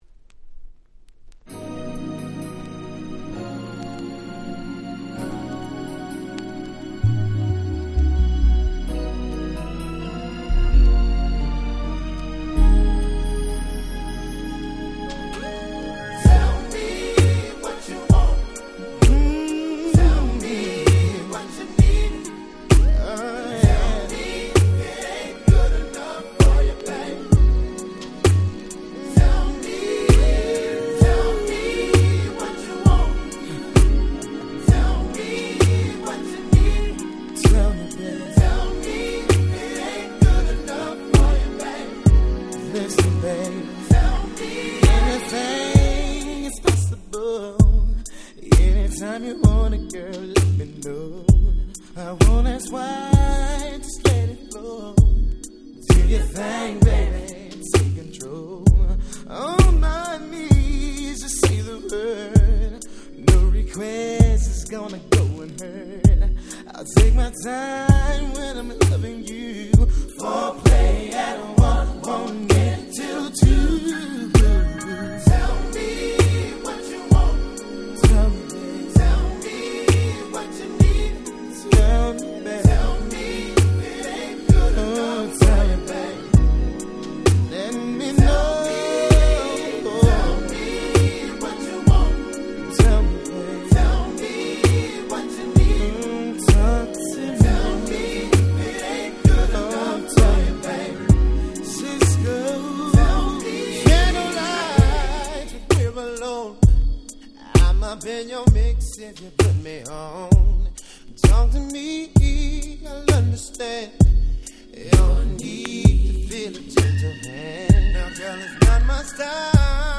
96' Super Hit R&B / Slow Jam.
彼らの真骨頂とも言える甘ったるいバラード。